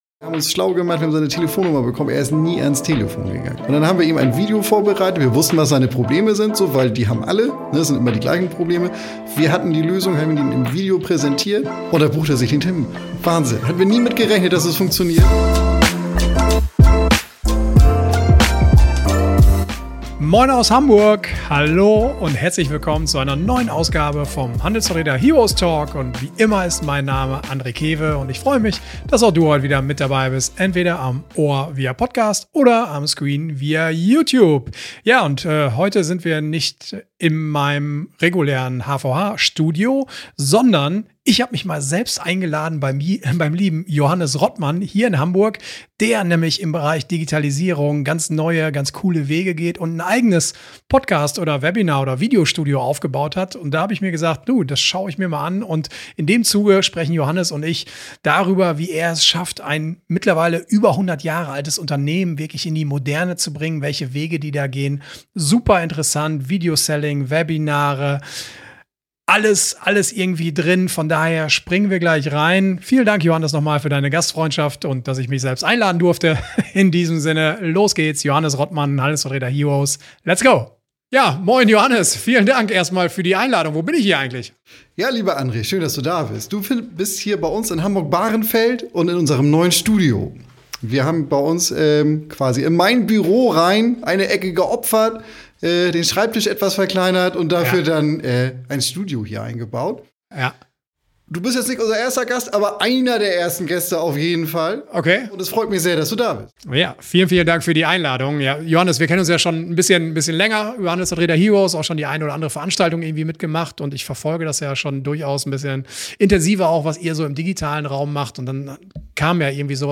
Dann entsteht ein Podcast-Gespräch über Digitalisierung mit Substanz.